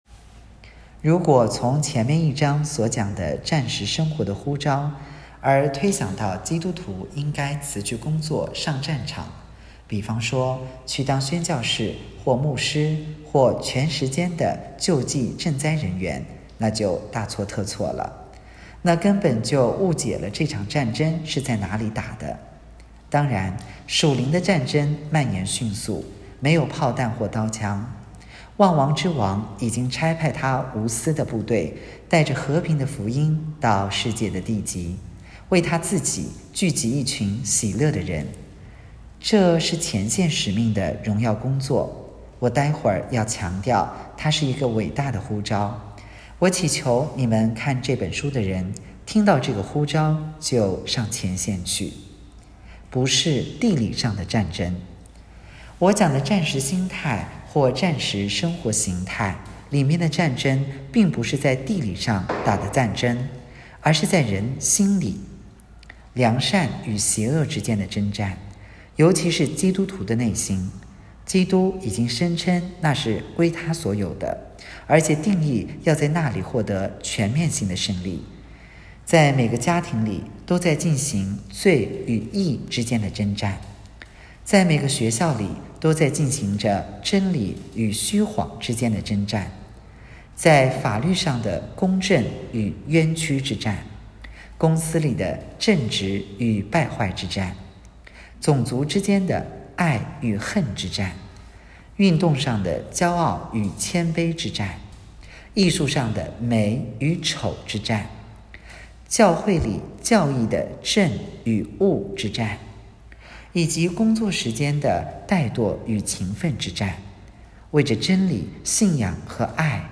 2024年6月24日 “伴你读书”，正在为您朗读：《活出热情》 欢迎点击下方音频聆听朗读内容 音频 https